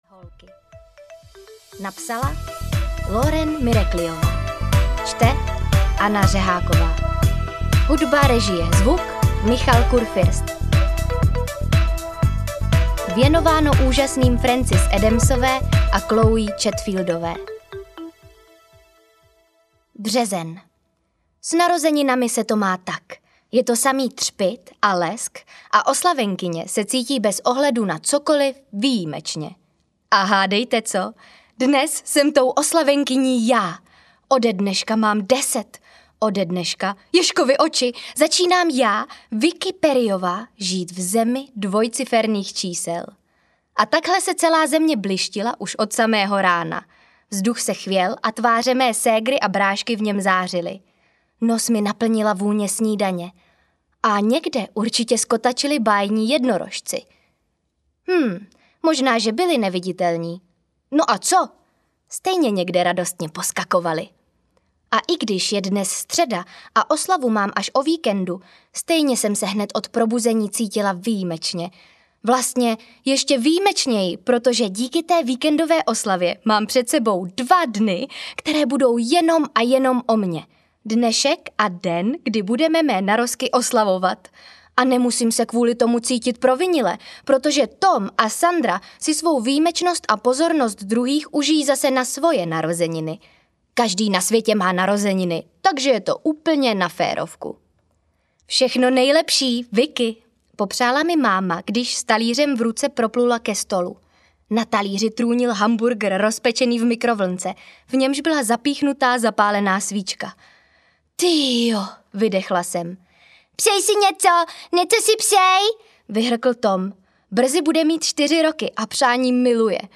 Deset audiokniha
Ukázka z knihy